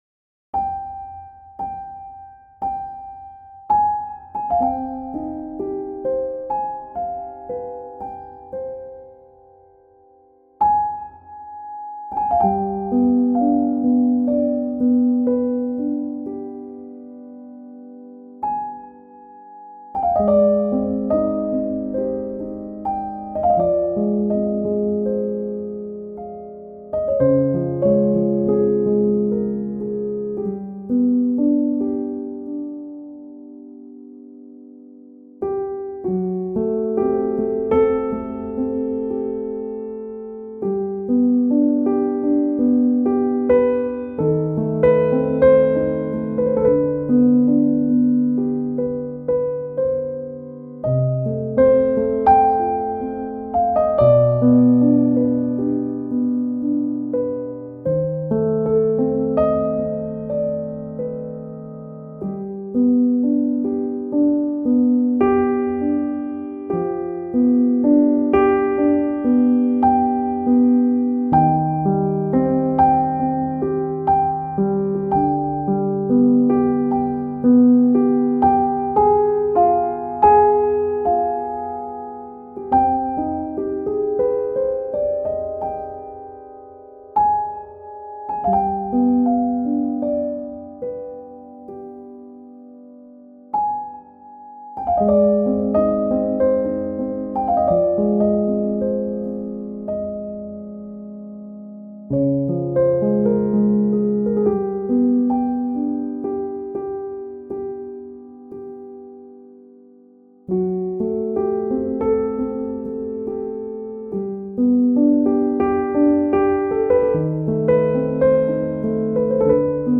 سبک پیانو , مدرن کلاسیک , موسیقی بی کلام